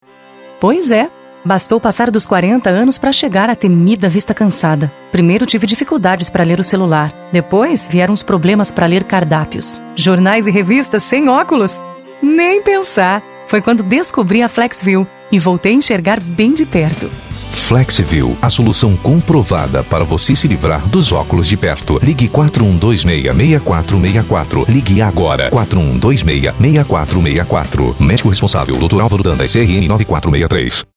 Sprechprobe: Industrie (Muttersprache):
The texts are really interpreted in the most correct way for the purpose of destination, the most jovial tone, retailer, and also interpretations for interaction with kids, happy, relaxed and dynamic reading, even the most serious, ceremonial readings and detailed jobs.